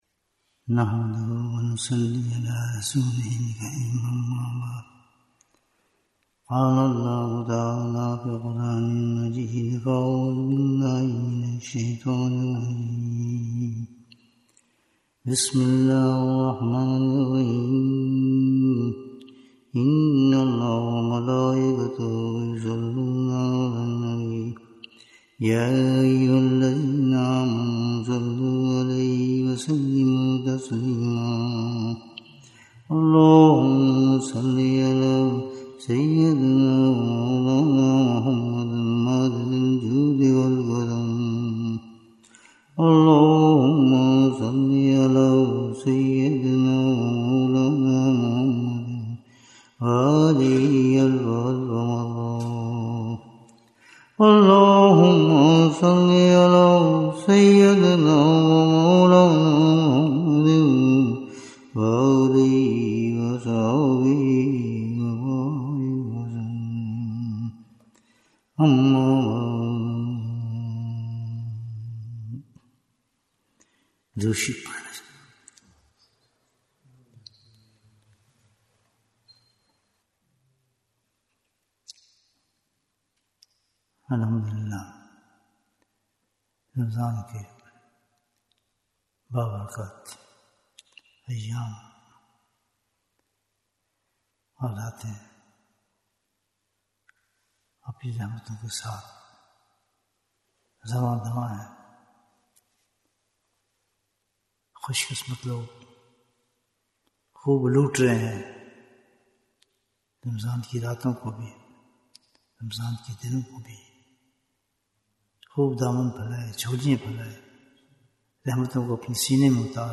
جواہر رمضان ۲۰۲۵ - ایپیسوڈ ۲۵ - بہتر اور افضل اسلام کون سا ہے؟ Bayan, 54 minutes19th March, 2025